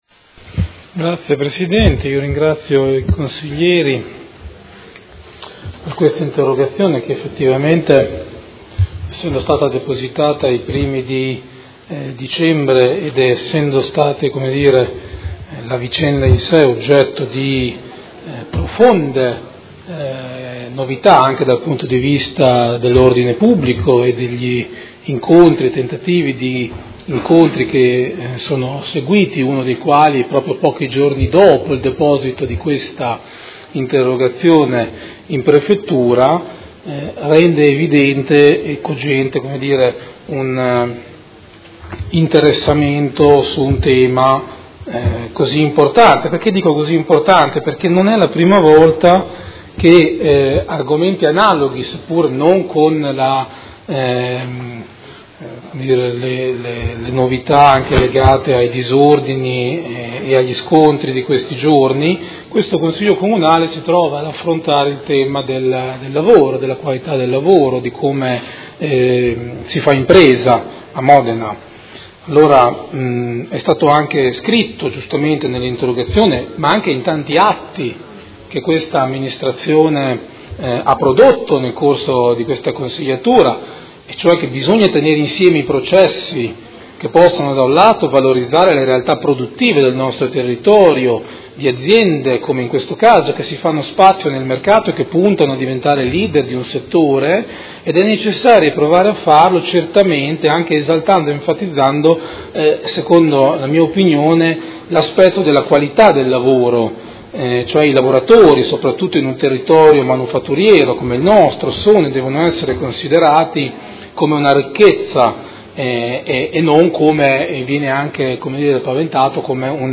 Seduta del 24/01/2019. Risponde a interrogazione del Gruppo Consiliare Art1-MDP/Per Me Modena avente per oggetto: Ore di forte tensione allo stabilimento Italpizza di San Donnino - Esigenze produttive e diritti dei lavoratori non sono disgiungibili - A che punto siamo con la conversione dei contratti?